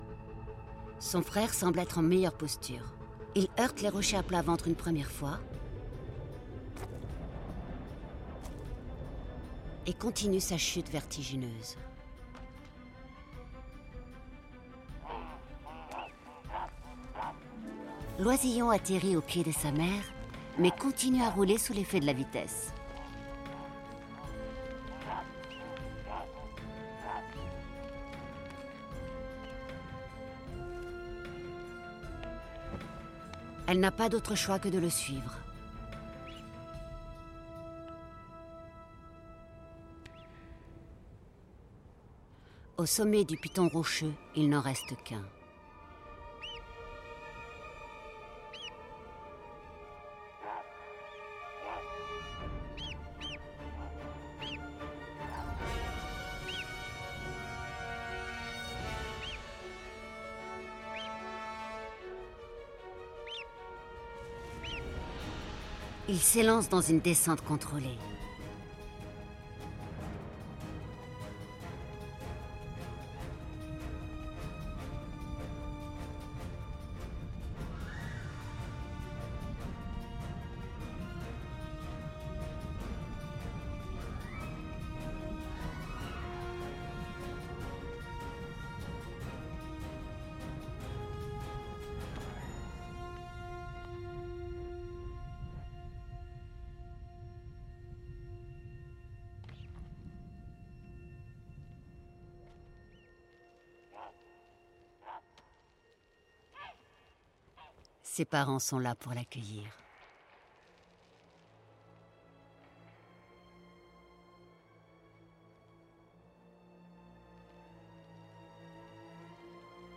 formation voix off casting